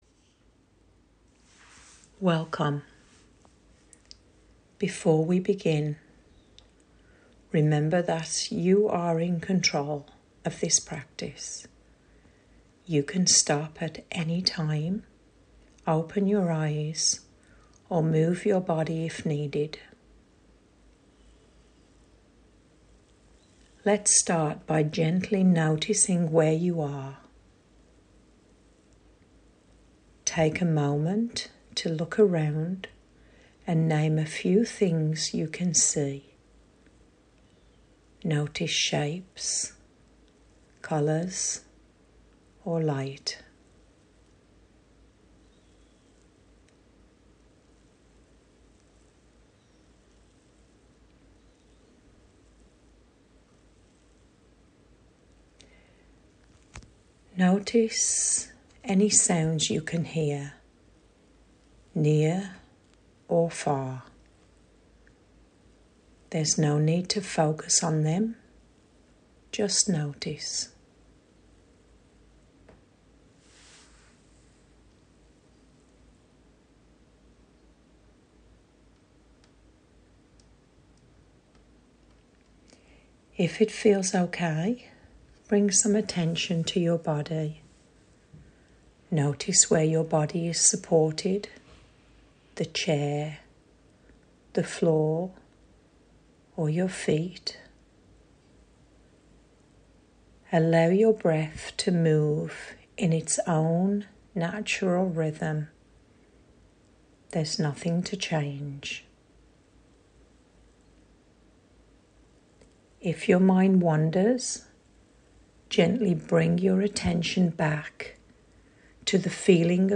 In the Stillness is a weekly therapeutic support workshop that offers a quiet, structured space for grounding, breathing, and guided meditation practices. The intention is to support nervous‑system regulation, present‑moment awareness, and a sense of internal safety.